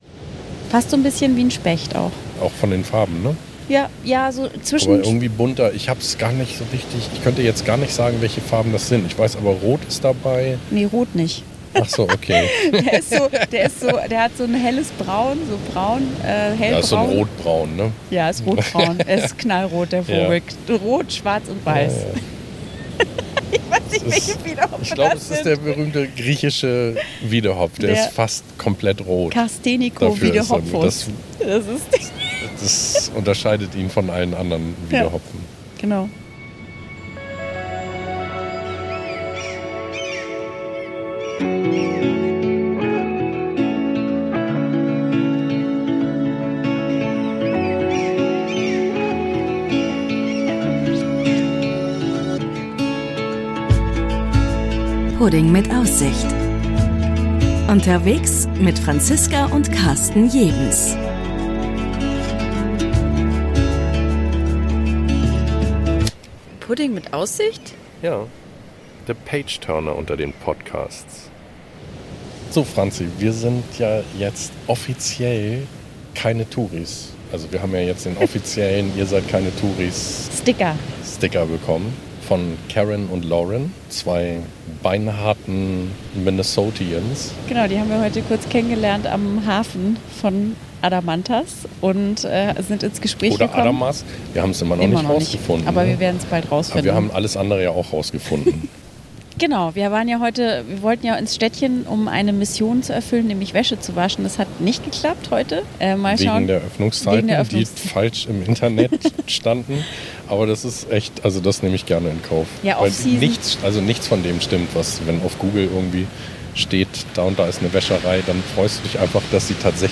Während in der Agápi-Bay der Sturm tost, machen wir es uns im Landy gemütlich und plaudern aus dem Reisenähkästchen.